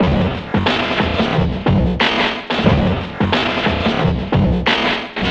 Index of /90_sSampleCDs/Spectrasonic Distorted Reality 2/Partition A/04 90-99 BPM